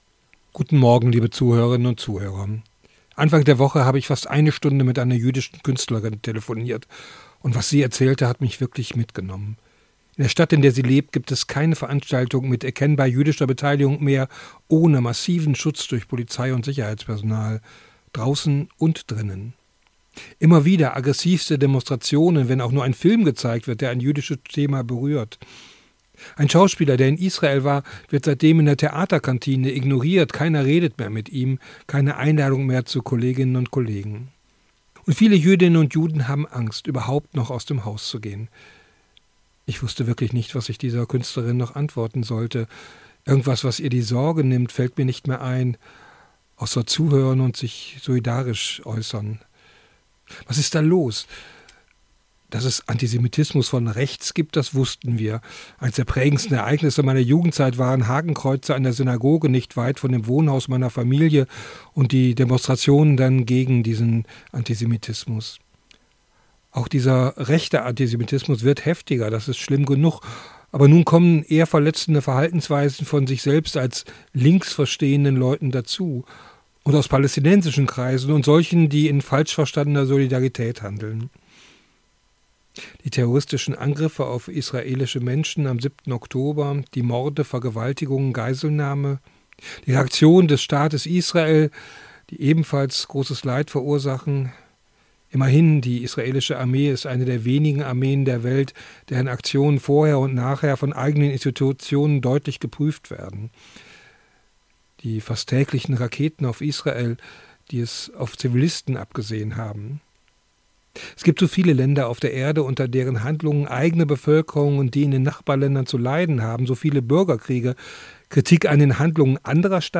Radioandacht vom 2. August